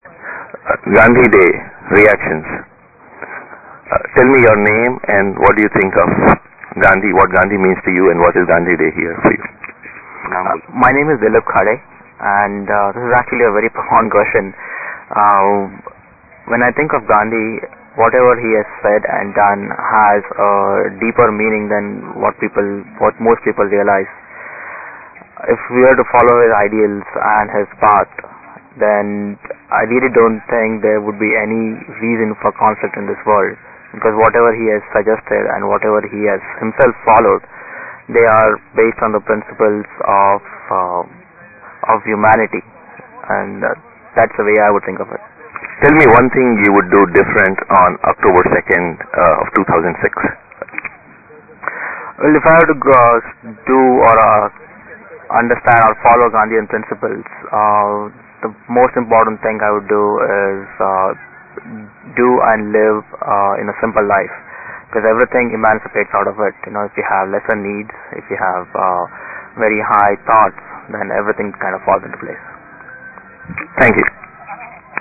MP3 Interviews
Interview1